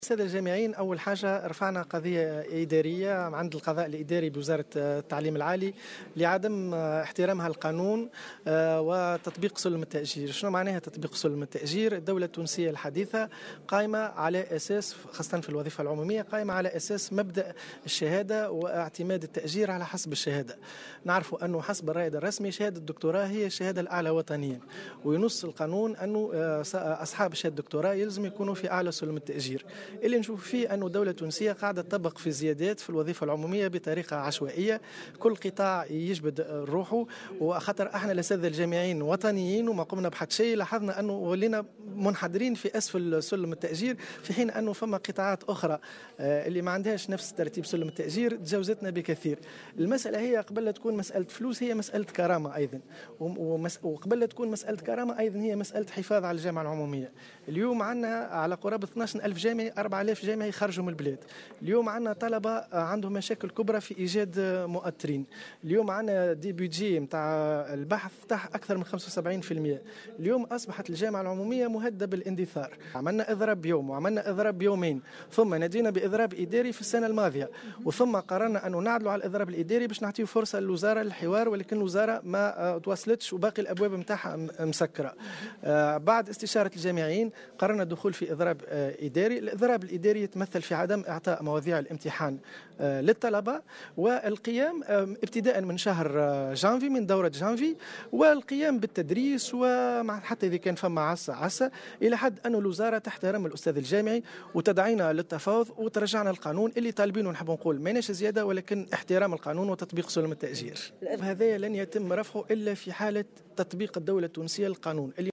وأضاف في تصريح لمراسل "الجوهرة أف أم" أنه تقرّر أيضا الدخول في إضراب إداري مفتوح بداية من الشهر القادم وذلك إلى حين الاستجابة لمطالبهم.